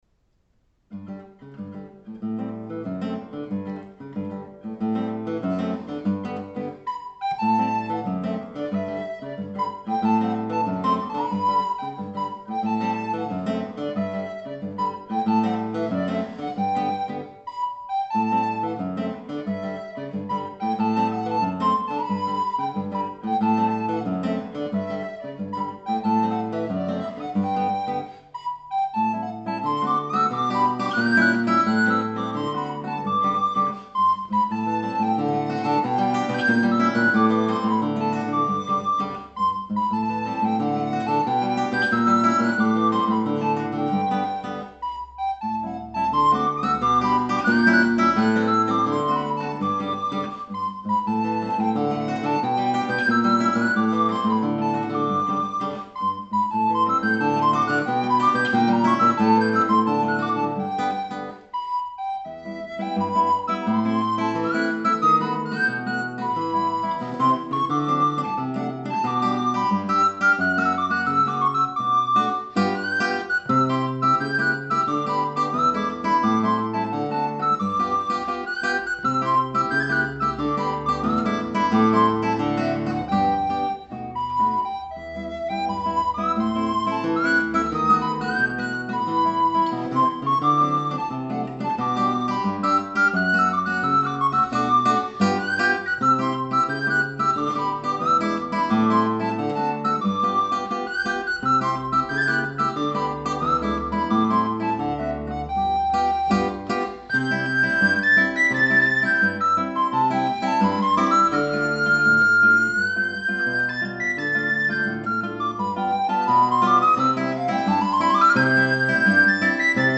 Barock –  baroque
Johann Sebastian Bach – sonate en trio pour orgue no 5 en fa majeur (BWV529)
clavecin
flûte à bec